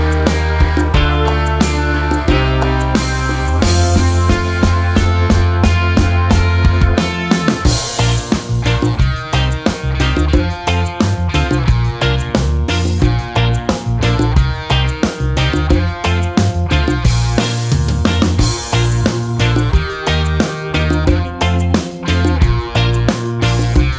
no Backing Vocals Pop (2010s) 4:22 Buy £1.50